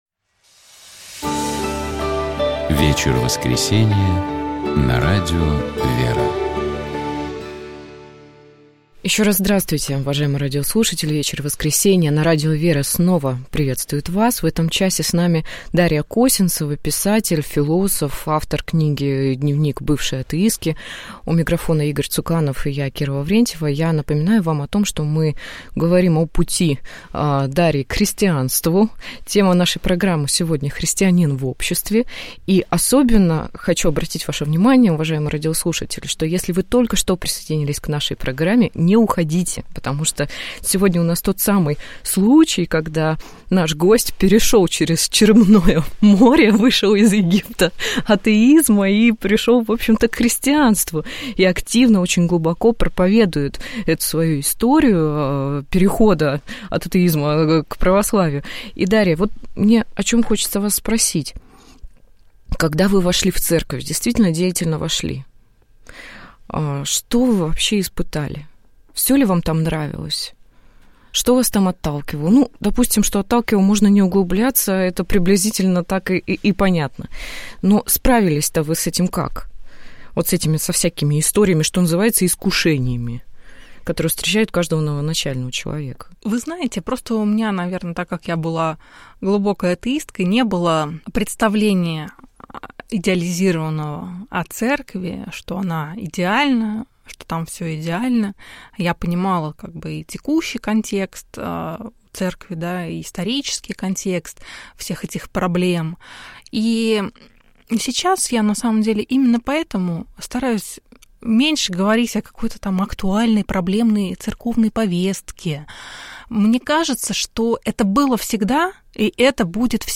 У нас в гостях была блогер, писатель